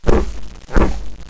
mummy_attack14.wav